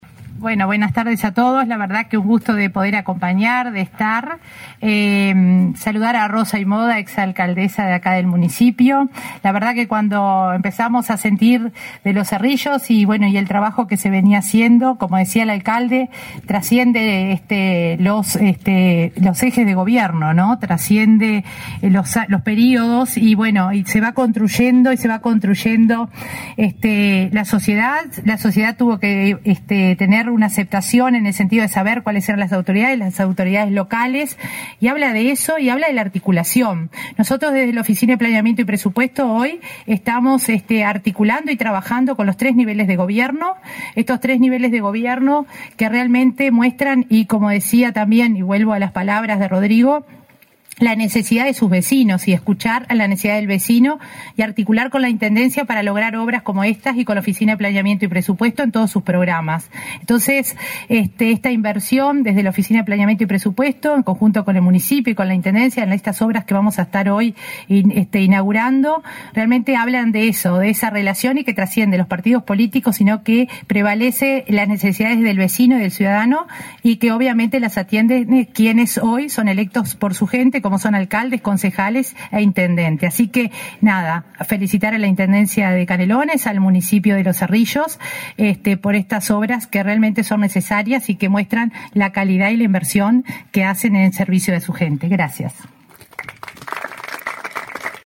Palabras de la coordinadora de Descentralización y Cohesión de OPP, María de Lima
Palabras de la coordinadora de Descentralización y Cohesión de OPP, María de Lima 14/03/2022 Compartir Facebook X Copiar enlace WhatsApp LinkedIn La Oficina de Planeamiento y Presupuesto (OPP) inauguró, este 14 de marzo, obras en el departamento de Canelones. La directora María de Lima participó en el evento.